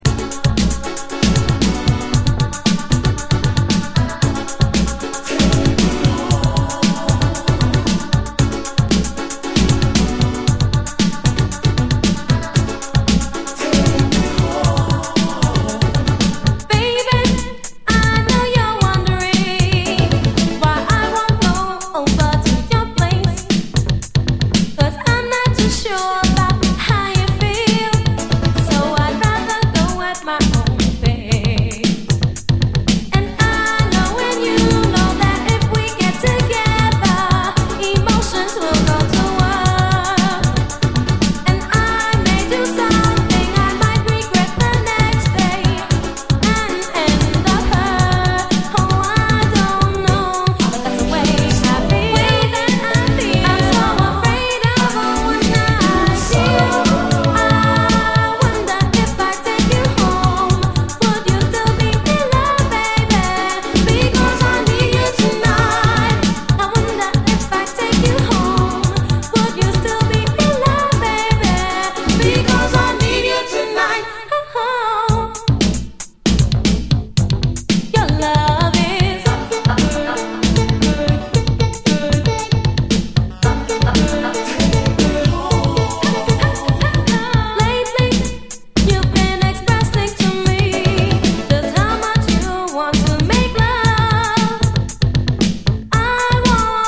潤いに満ちたギターの音色で心を奪われる、静かな至高メロウ・スピリチュアル・ジャズ